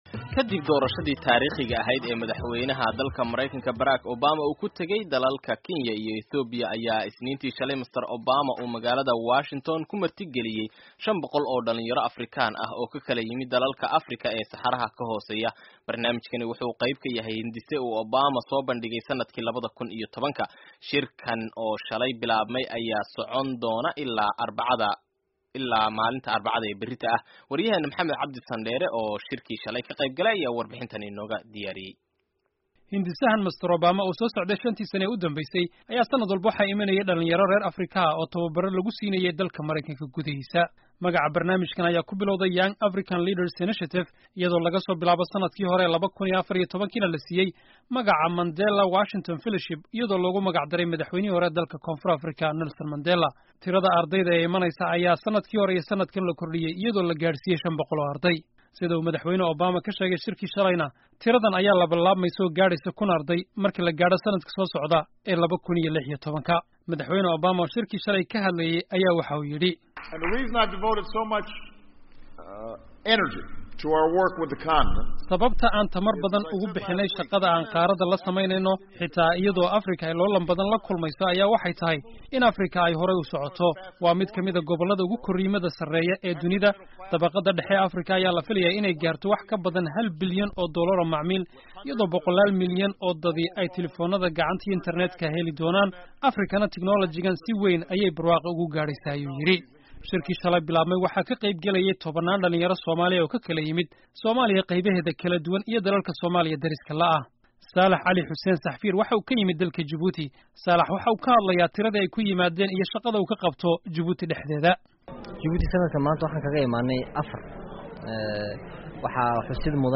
Obama oo la Hadlay Dhalinta Africa ee YALI
Madaxweynaha Maraykanka Barack Obama ayaa Washington kula kulmay in kabadan 500 oo dhalinyaro African ah oo ka qeybgalayay barnaamijka "YALI". Obama wuxuu dhalinyaradan ku booriyay inay sii wadaan horumarka qaaraddu ilaa hadda gaartay.
Warbixinta YALI